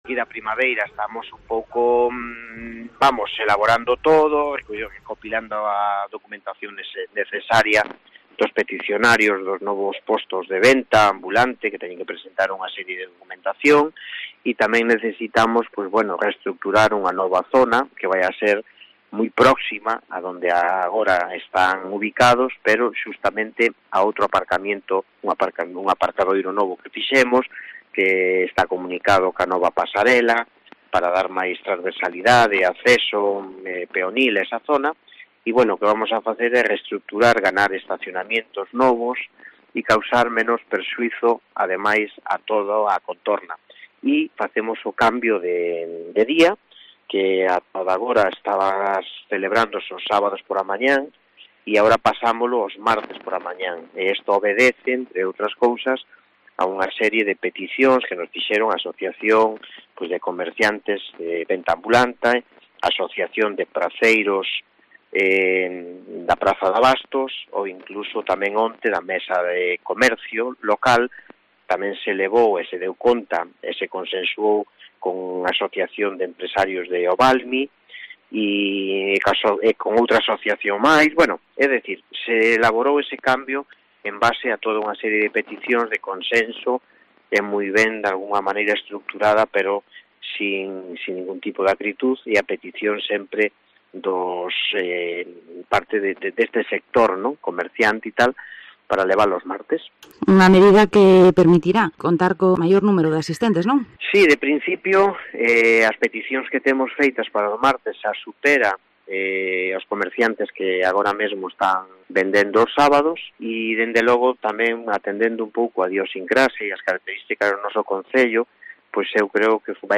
Gondomar prevé modificar el día y lugar de su mercadillo, nos lo cuenta su Alcalde, Paco Ferreira